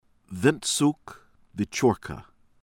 SIDORSKY, SERGEI sir-GAY    sih-DOHR-skee